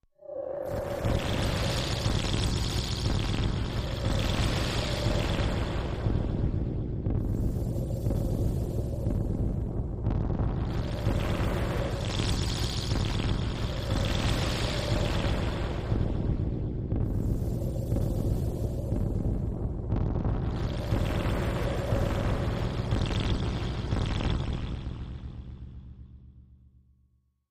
Fluid Alignment, Machine, Underwater, High Ice, Pulsing, Reverb